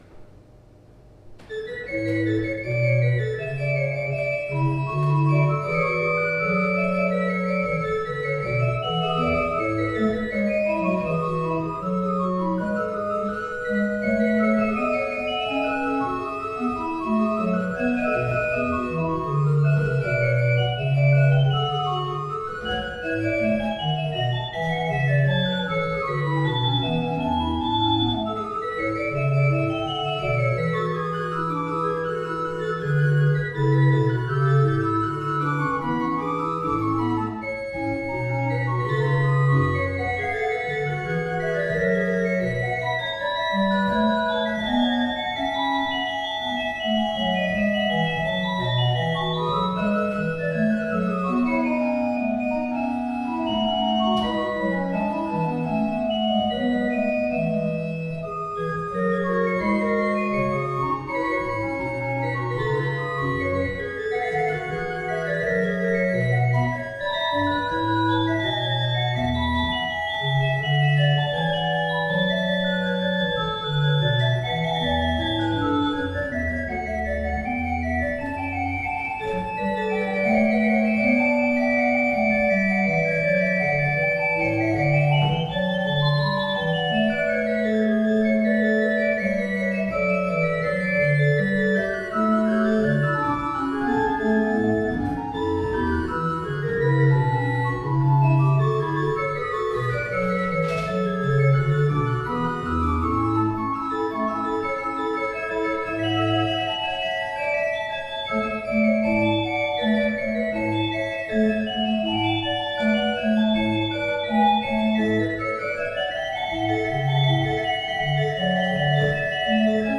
The casework and stop knobs are based on an 18th century English style as is the general sound of the instrument.